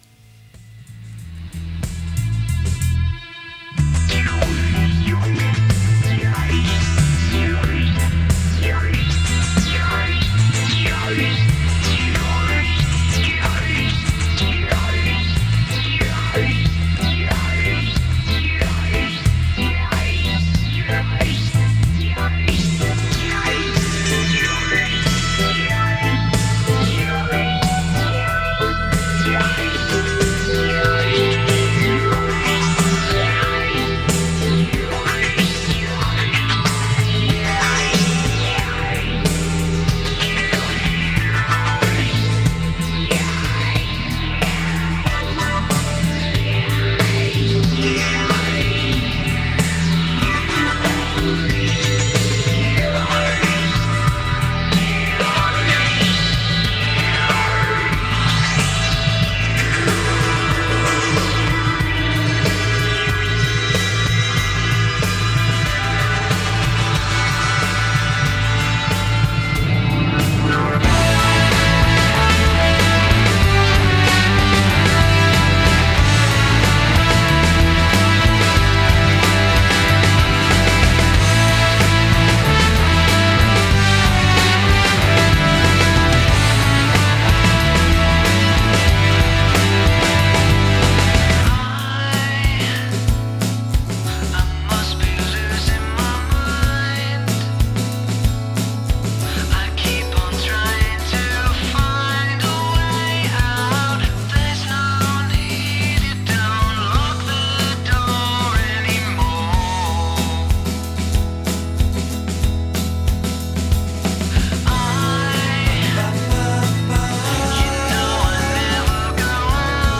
Groupe de rock anglais originaire de Liverpool.